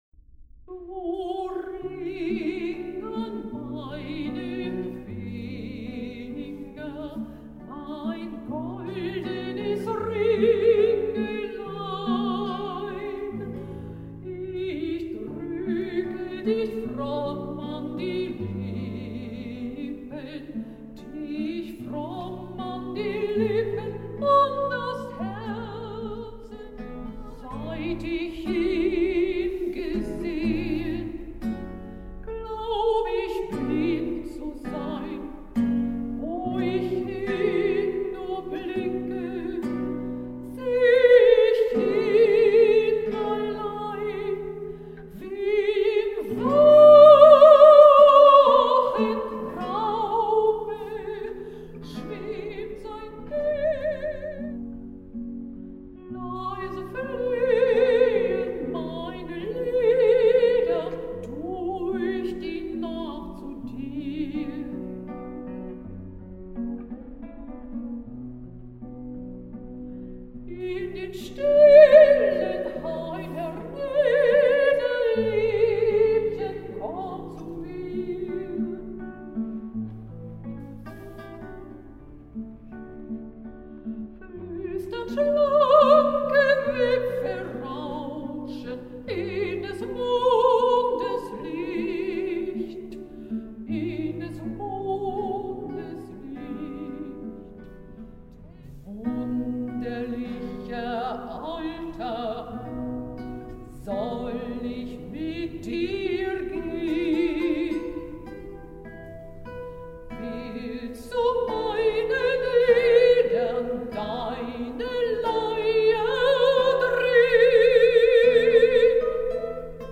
Mein relativ neues Projekt: Klassische Lieder und Arien mit Gitarrenbegleitung, perfekt für ein Konzert in kleinem Rahmen…..
an der Gitarre